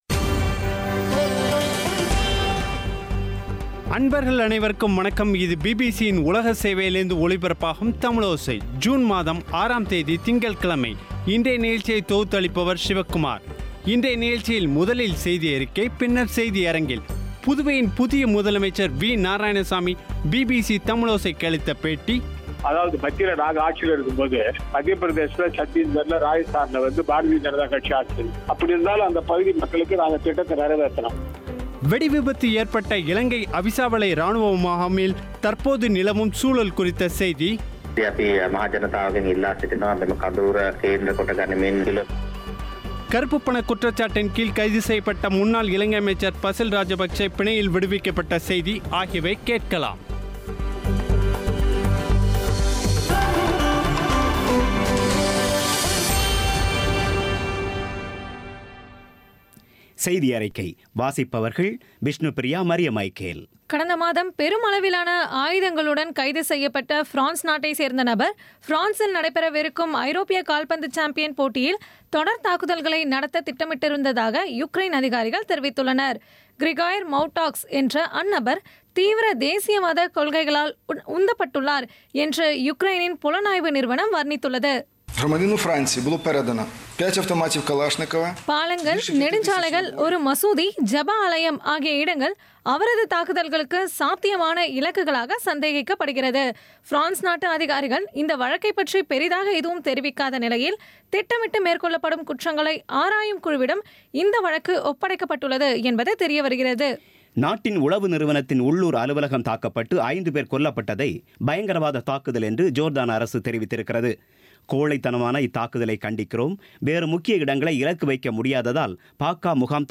இன்றைய நிகழ்ச்சியில் முதலில் செய்தியறிக்கை பின்னர் செய்தியரங்கில்
புதுவையின் புதிய முதலமைச்சர் வி.நாராயணசாமி, பிபிசி தமிழோசைக்கு அளித்த பேட்டி